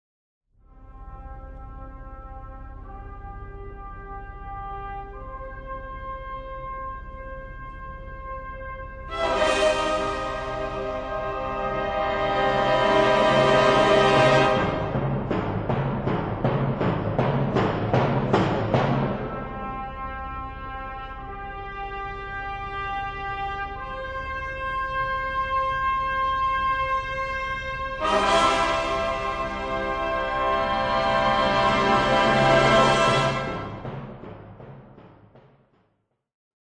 Confrontalo con l'inizio del poema sinfonico Così parlò Zarathustra di R. Strauss.